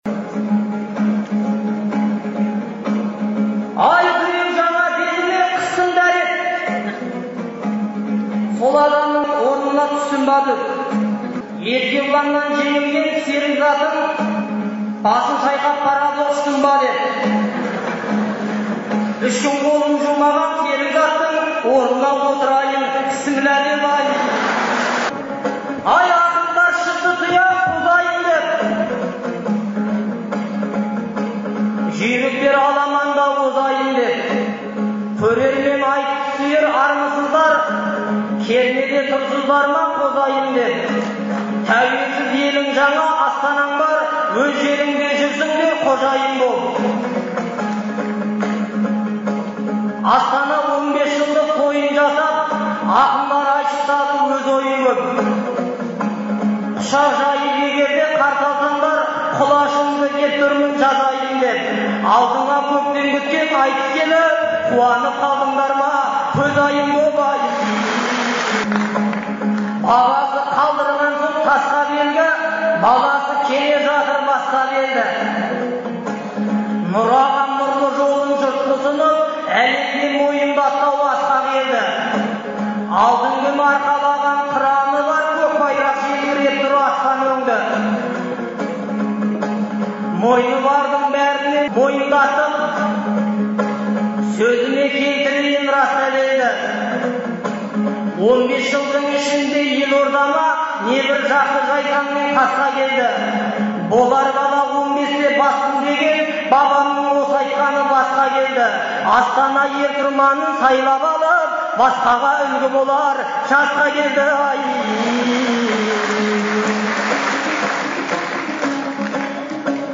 айтысы
Шілденің 8-9-ы күндері Астанада қала күніне орай «Ел, Елбасы, Астана» деген атпен ақындар айтысы өтті.